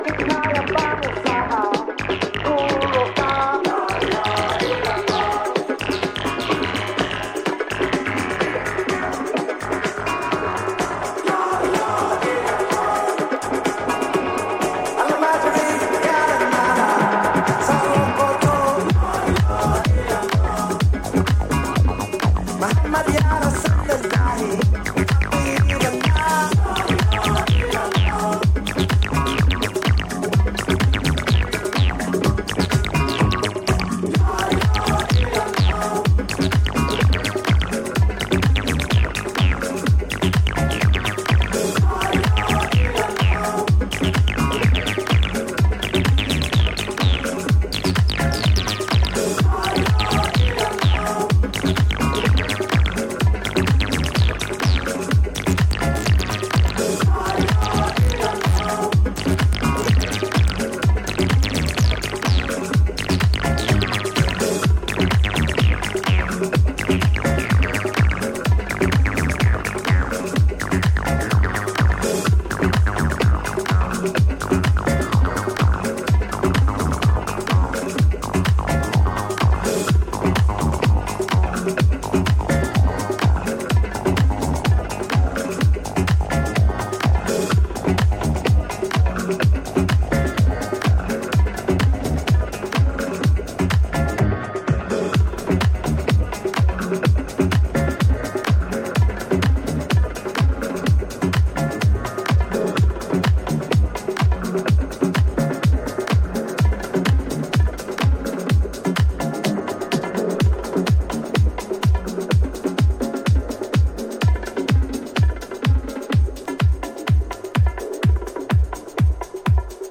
sizzling hot party music
edits and re-rubs
there's a particular emphasis on acid
whipping up a veritable storm